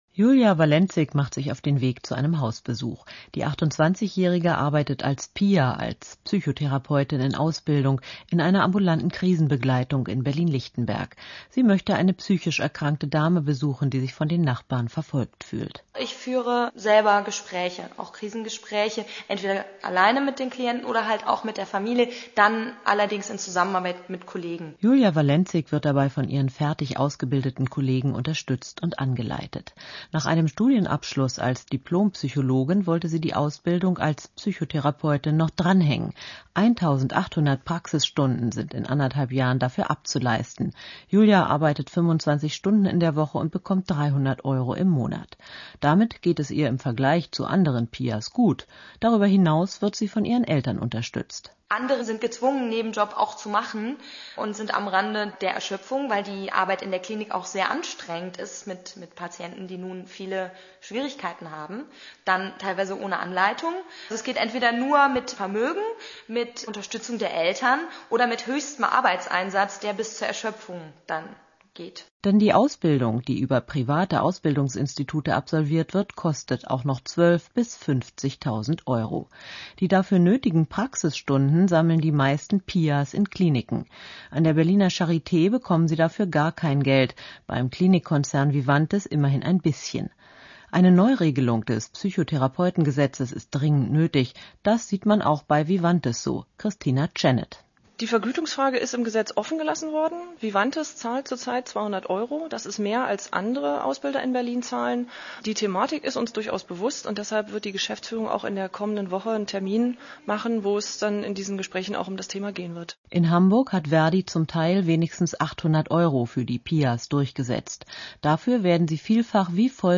Ein Rundfunkbeitrag